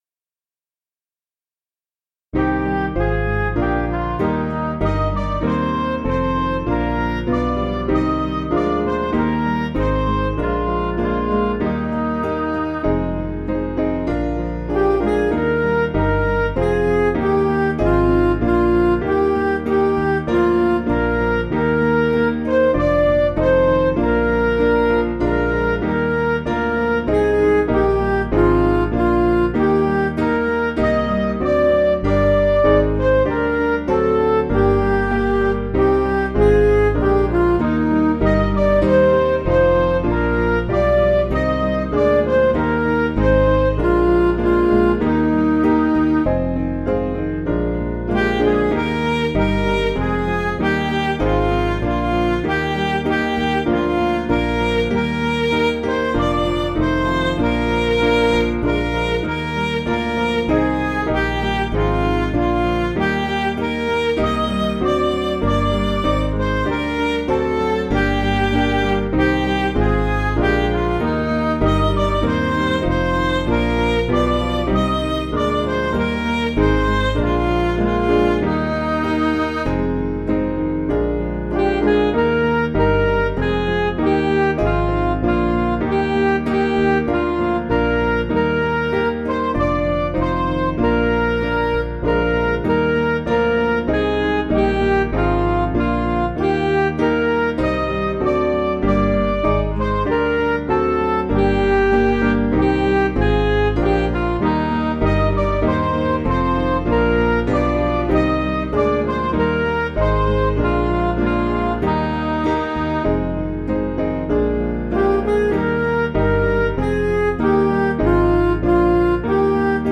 (CM)   5/Eb
Midi